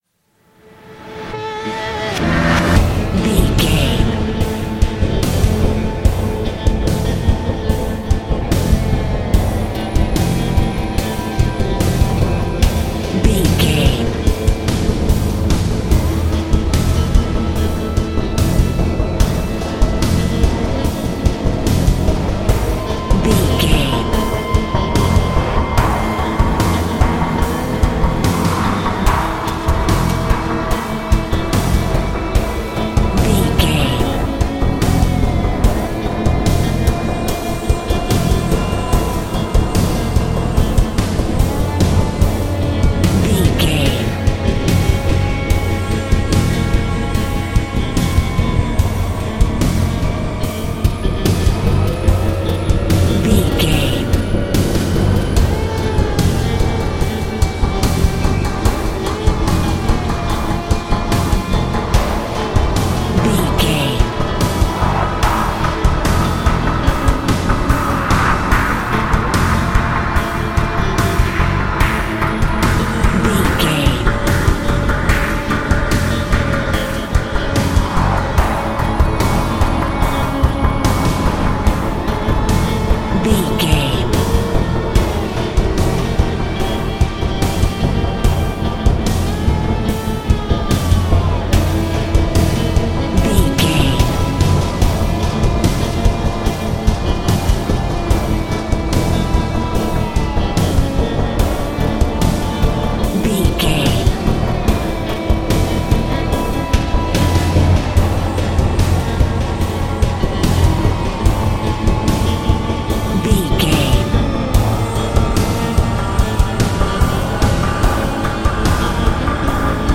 Thriller
Aeolian/Minor
synthesiser
drum machine
electric guitar